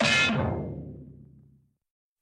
CARTOON - BANG 02
Category: Sound FX   Right: Both Personal and Commercial